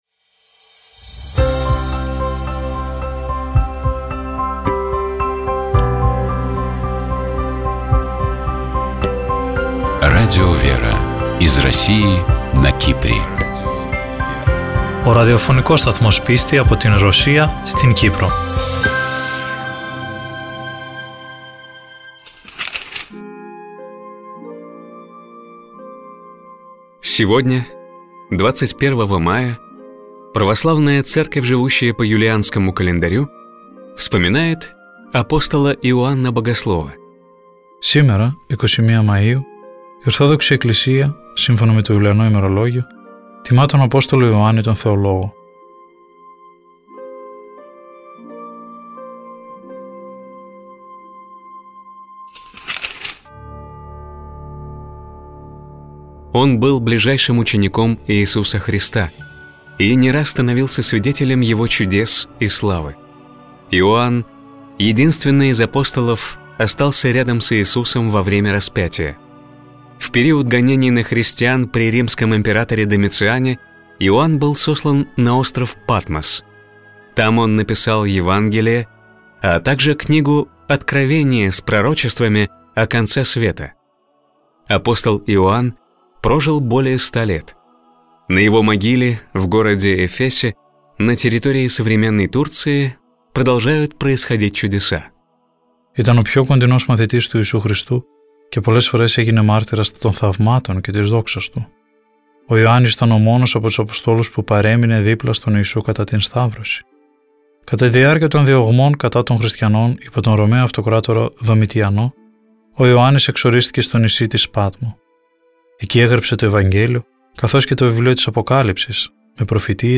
«Зачем философия сегодня?» Интервью